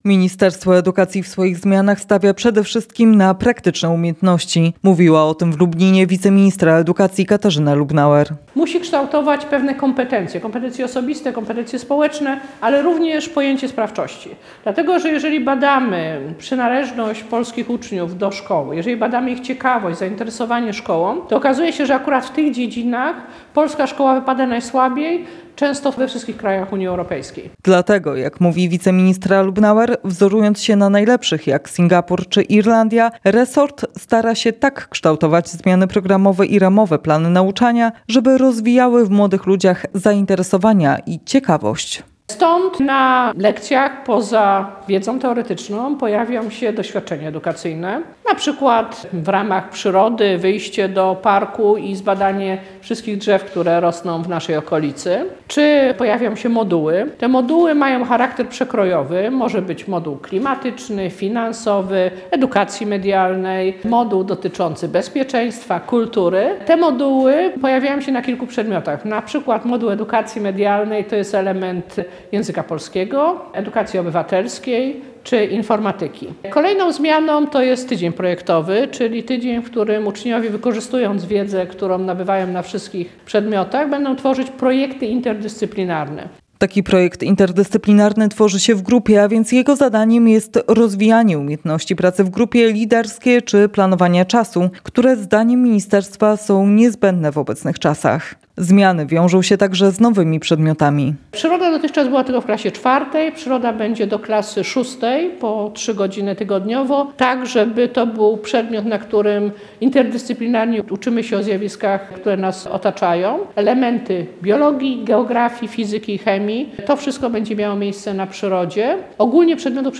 O zmianach mówiła dziś w Lublinie wiceministra edukacji Katarzyna Lubnauer.